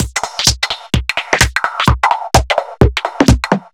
Index of /musicradar/uk-garage-samples/128bpm Lines n Loops/Beats
GA_BeatResC128-02.wav